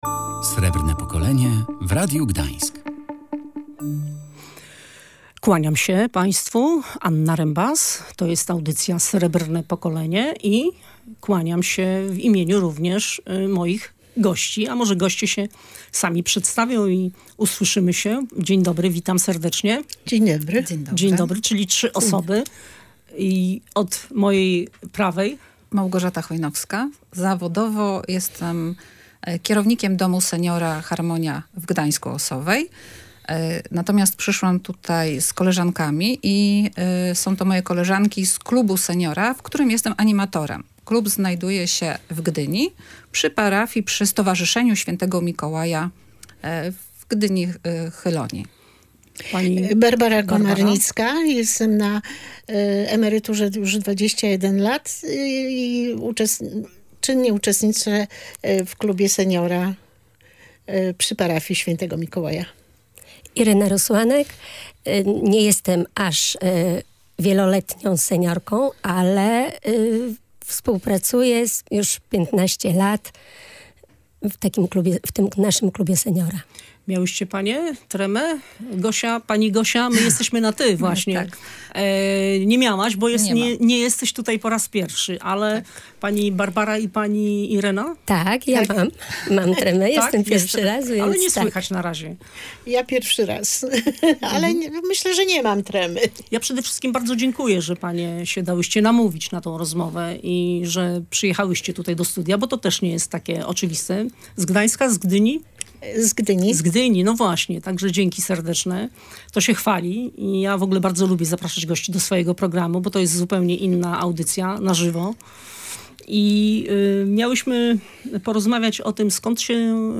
byli seniorzy